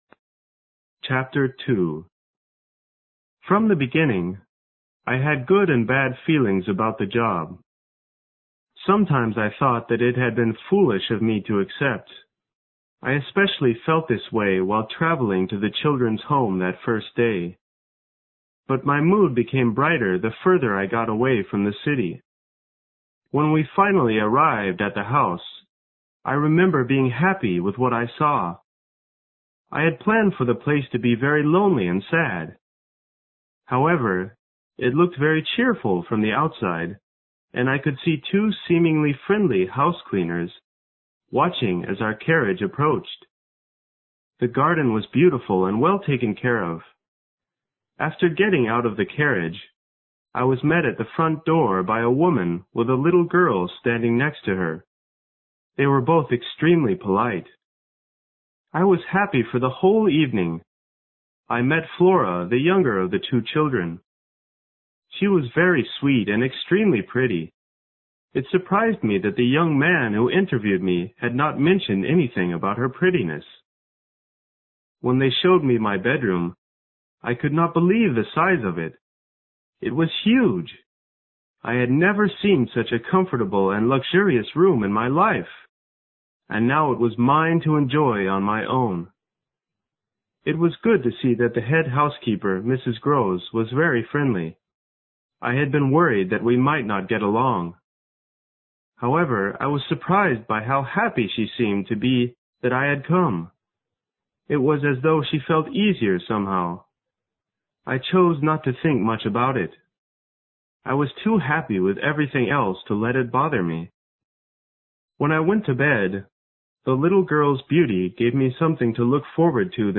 有声名著之螺丝在拧紧chapter2 听力文件下载—在线英语听力室